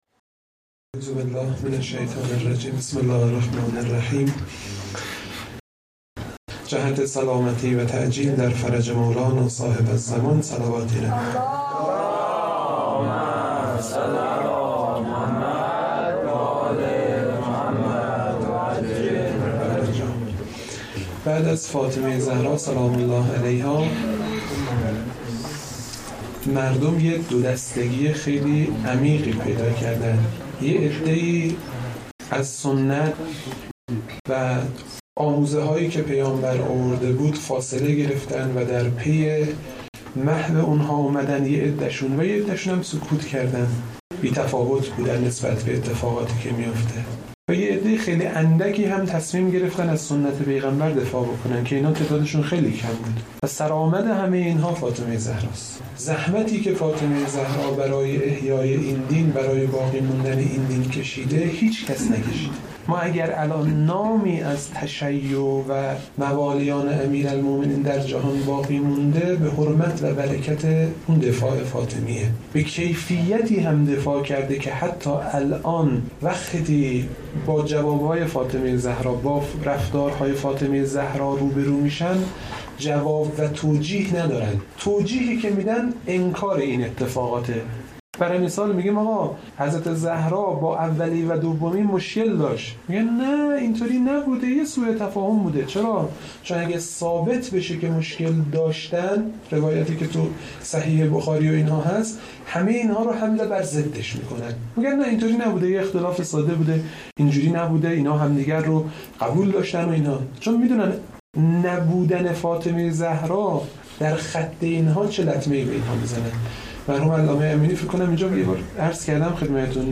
منبر شب سوم فاطمیه 1393